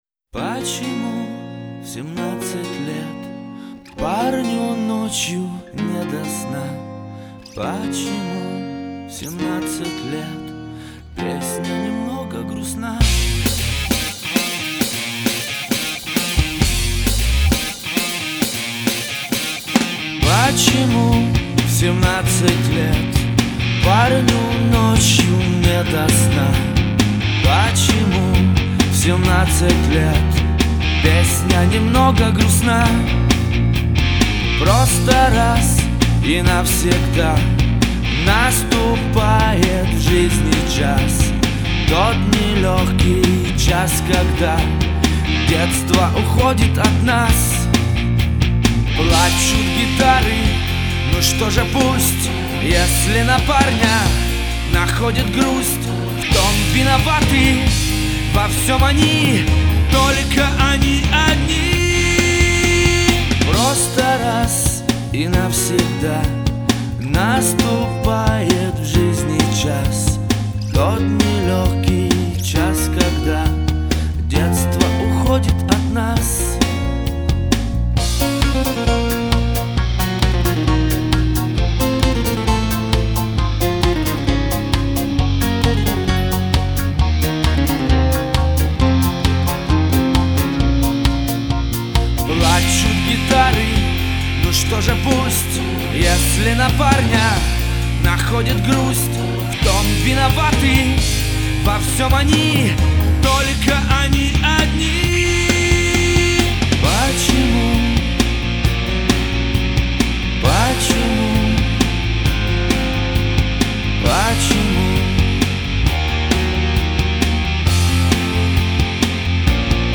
альтернативная рок-группа